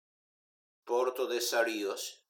Prononcer "Salïes".